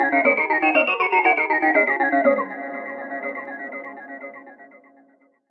Synth Loops " Repose Lost Melody
描述：丢失的旧恍惚轨道的旋律。非常宽敞，有很多混响。
标签： 循环 钢琴 旋律 斯派西 混响 空灵 音乐 电子乐 养神 空间 精神恍惚 舞蹈 和弦 失落 大气 阿普
声道立体声